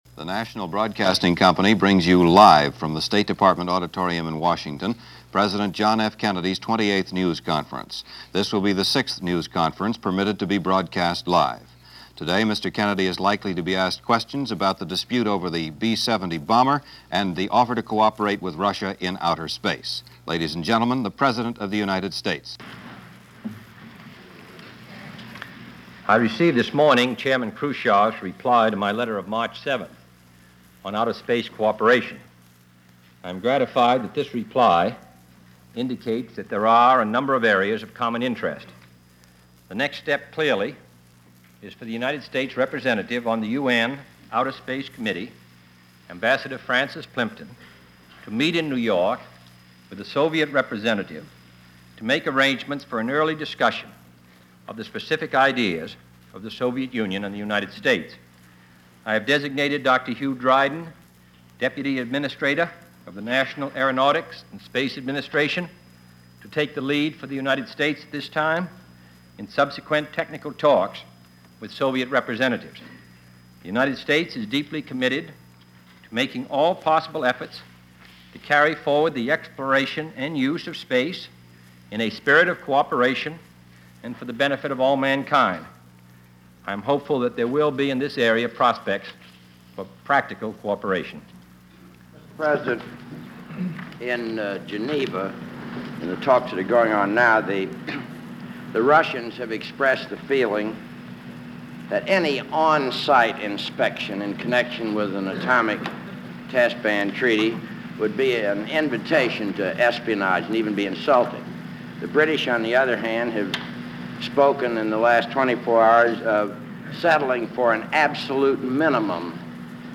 March 21,1962 - President Kennedy's 28th Press Conference - Past Daily Reference Room
March 21, 1962 – On this day, President Kennedy held his 28th Press conference since taking off in January of 1961. He discusses the Space program, Cuba, the United Nations and the NATO alliance.